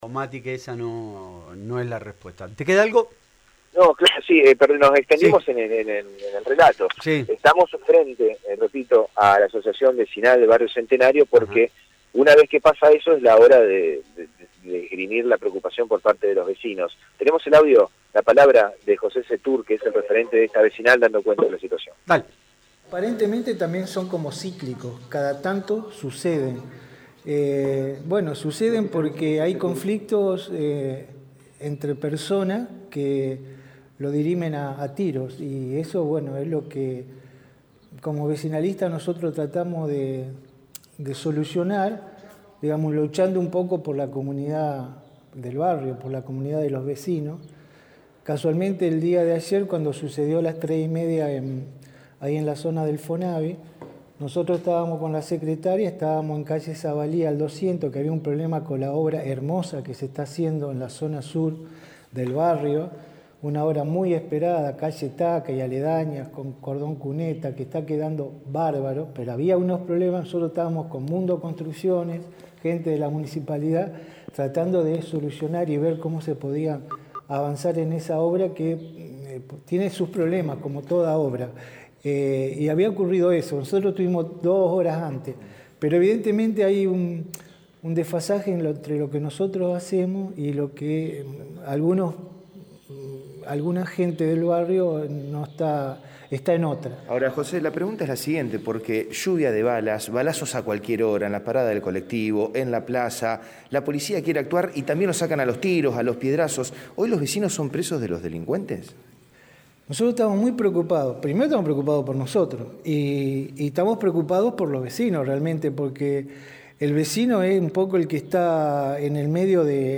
AUDIO DESTACADOSanta Fe